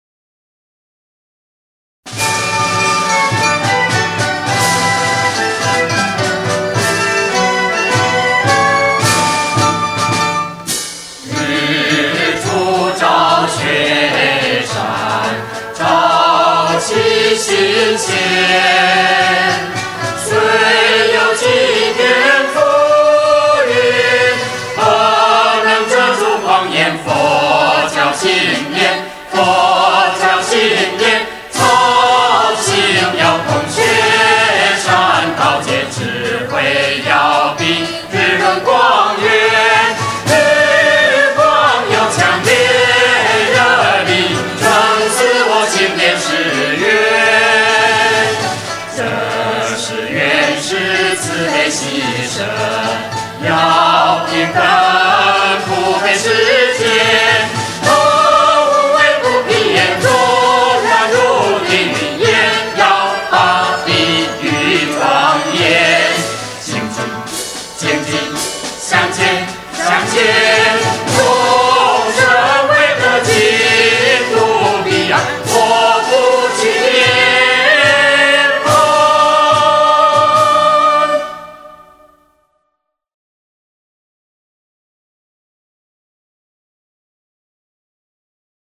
（十二）佛歌
李炳南編詞/陳關生作曲